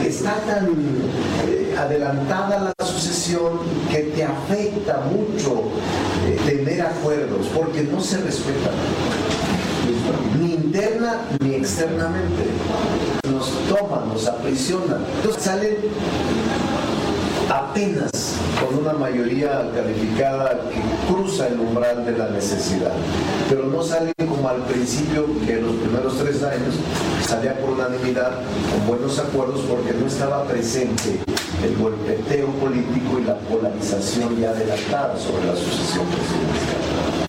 En el marco de la cátedra que imparte a los alumnos de la maestría de Derecho de la UNAM, el presidente de la Junta de Coordinación Política se refirió a los recientes nombramientos de dos nuevos comisionados del Instituto Nacional de Transparencia, Acceso a la Información y Protección de Datos Personales (INAI).